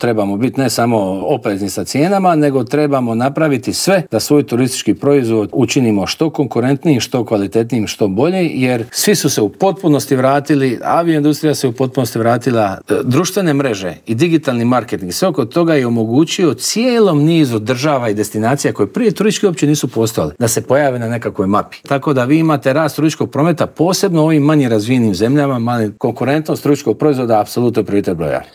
Inače, u prvih osam mjeseci došlo nam je 17,1 milijuna turista što je 2 posto više nego 2024. godine dok smo zabilježili i porast noćenja od 1 posto te ostvarili 89,9 milijuna noćenja o čemu smo u Intervjuu tjedna Media servisa razgovarali s ministrom turizma i sporta Tončijem Glavinom.